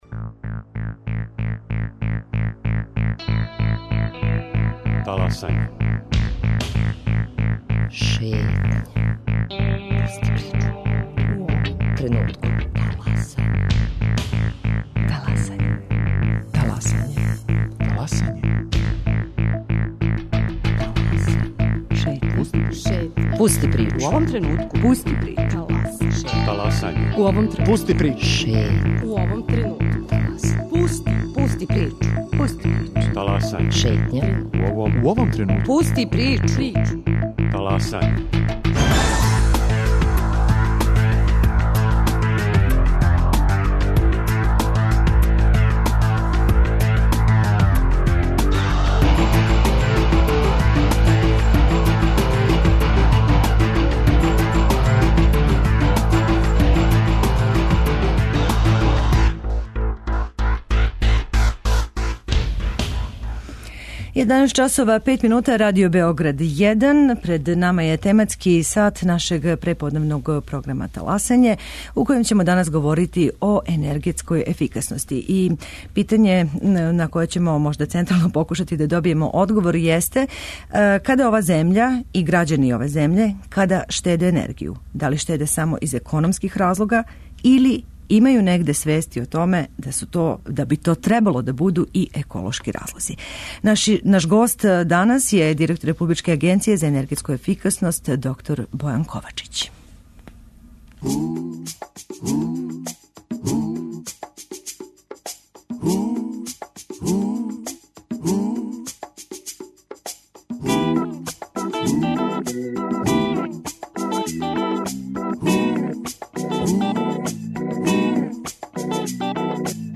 У другом сату, гост ће нам бити директор Републичке агенције за енергетску ефикасност др Бојан Ковачић.